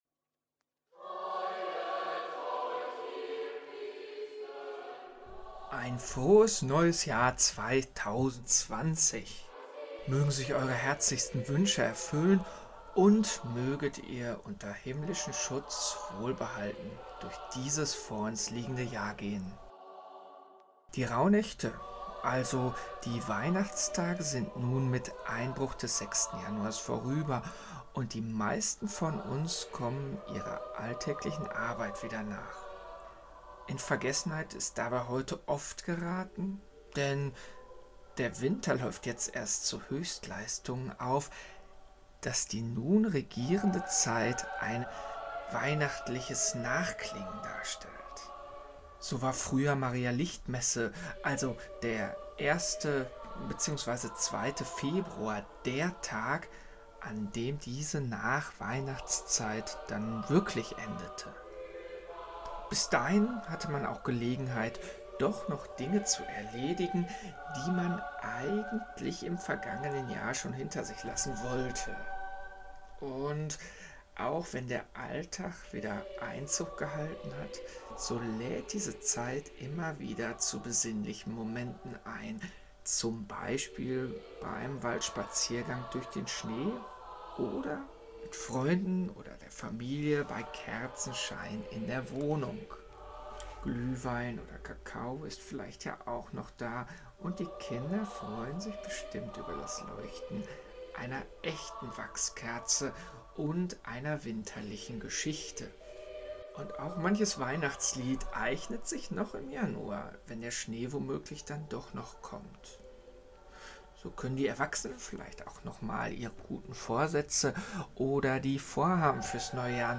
Die Aufnahme des Liedes im Hintergrund (Freuet euch, ihr Christen alle) mag an die 50 Jahre alt sein.